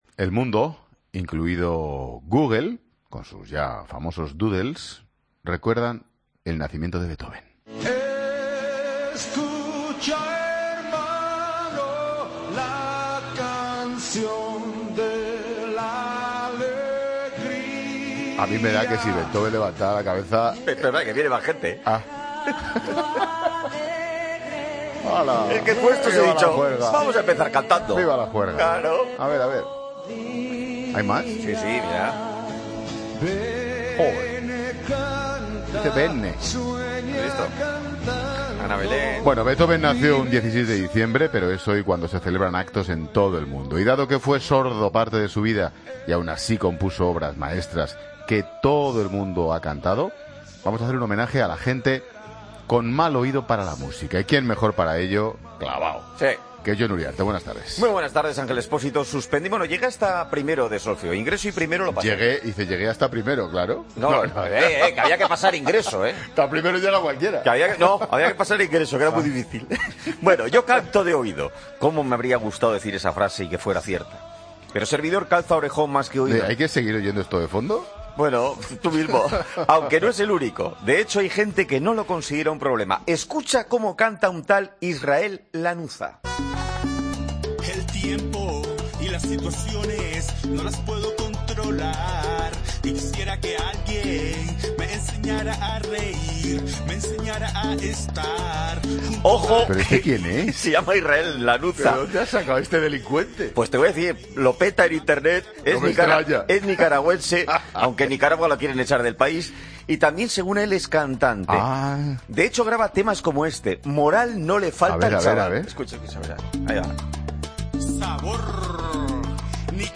AUDIO: En la celebración del nacimiento de Beethoven hacemos homenaje a esos cantantes que no siendo sordos tampoco es que tengan muy buen oído.